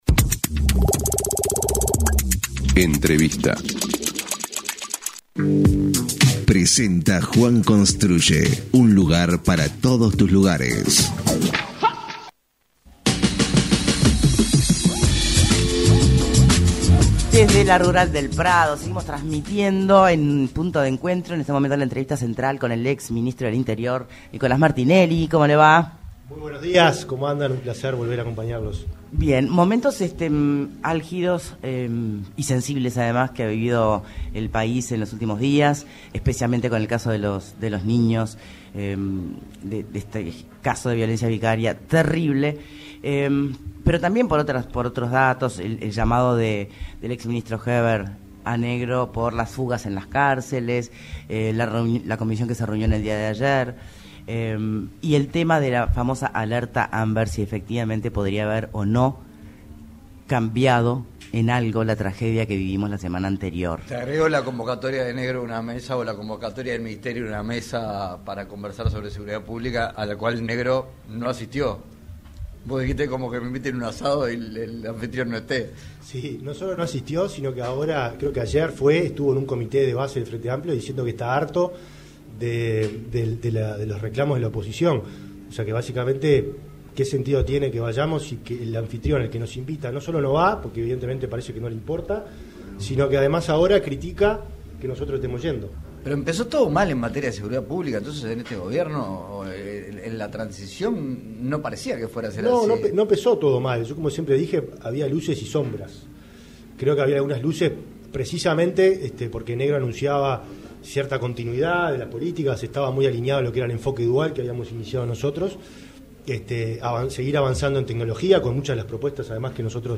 En entrevista con Punto de Encuentro desde la Expo Prado en la transmisión especial de 970 Universal, el exministro del Interior, Nicolás Martinelli cuestionó que el ministro del Interior Carlos Negro no concurre a las convocatorias del Diálogo por la Seguridad con todos los partidos y que además critica a la oposición, con lo que el Partido Nacional debe replantearse si tiene que seguir participando de ese ámbito.